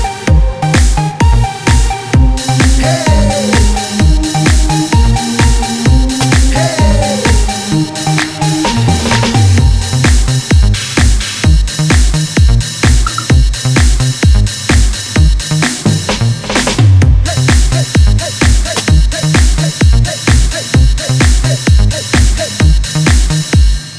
For Male Female Duet R'n'B